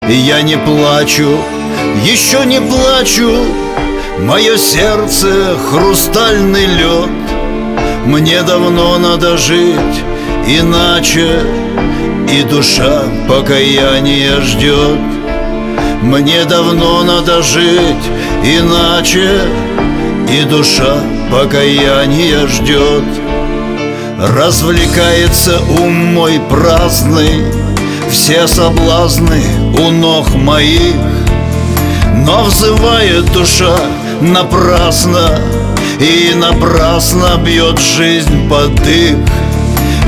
• Качество: 256, Stereo